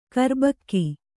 ♪ karbakki